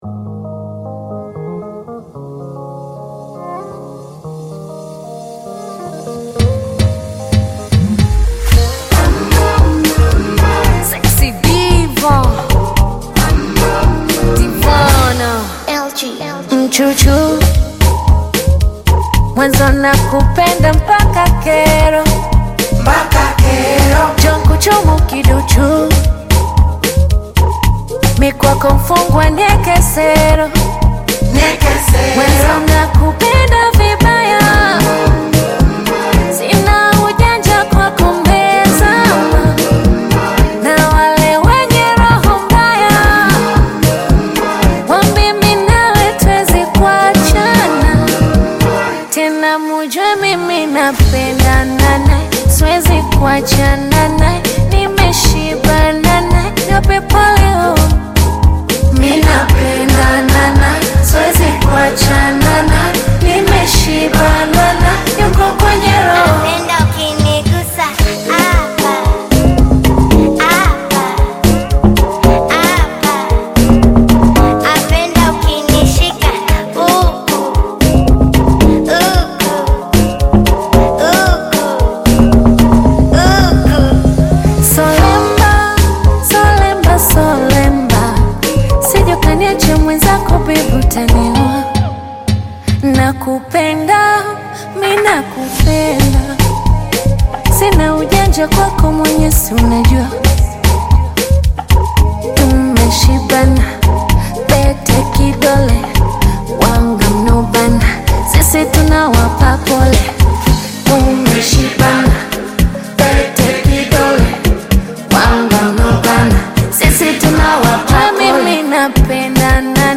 romantic love song